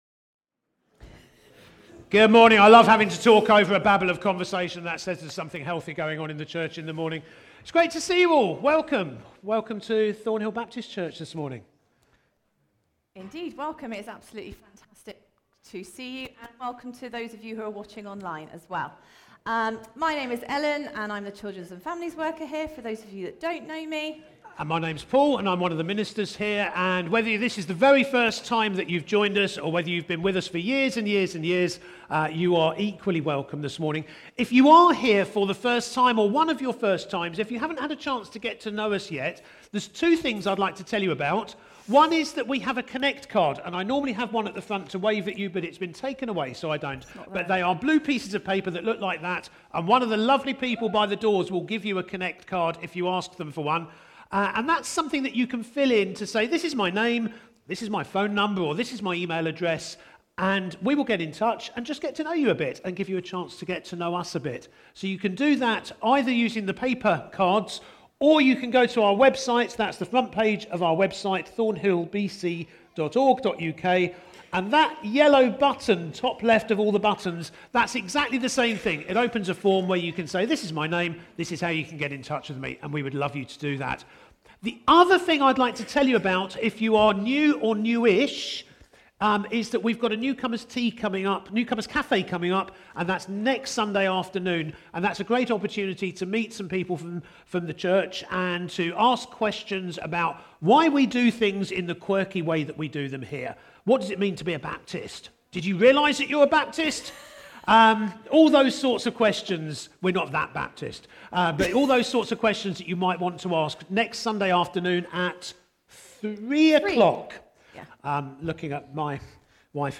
A message from the series "Stand Alone Sermons 2024."